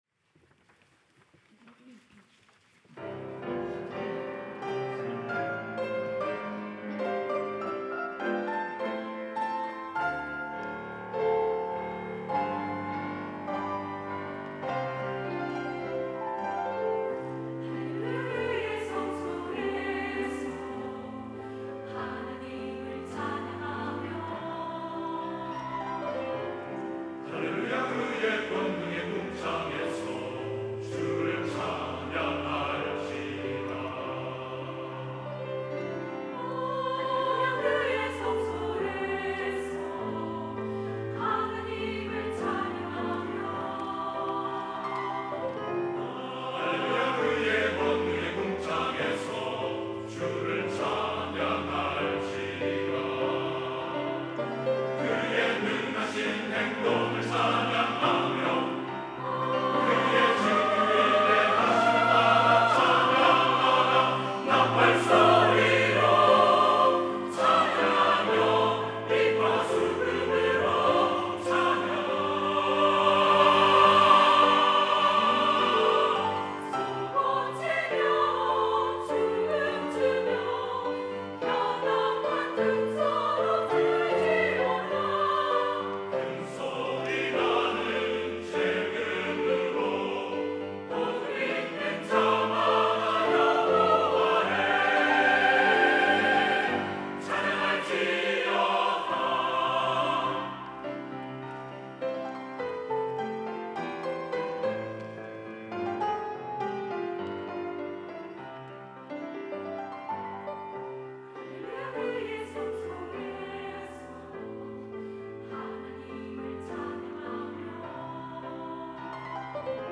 특송과 특주 - 할렐루야 그의 성소에서
연합 찬양대